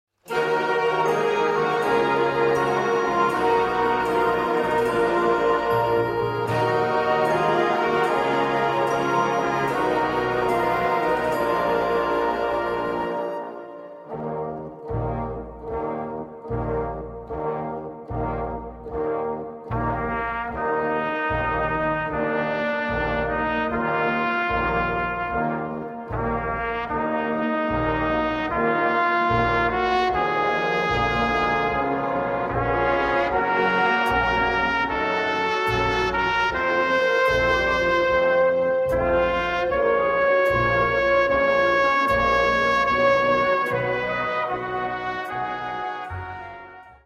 Gattung: Solo für Trompete und Blasorchester
Besetzung: Blasorchester